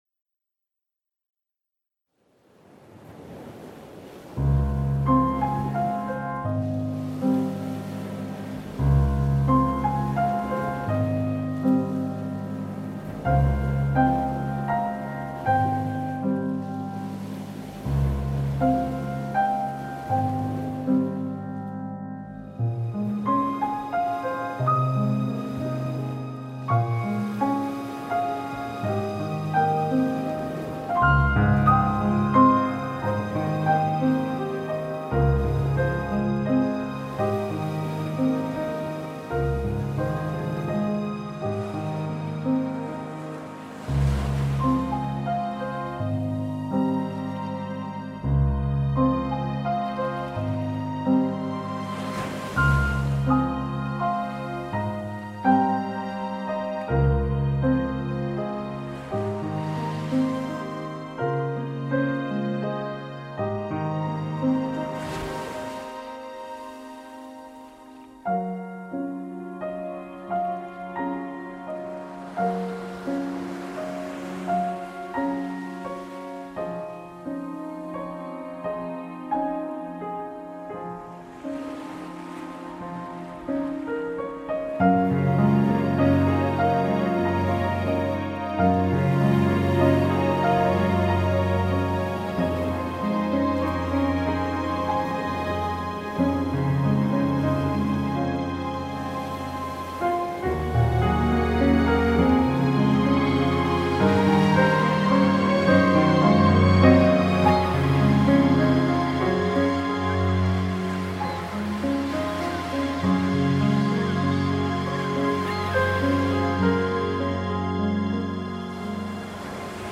Nature1.mp3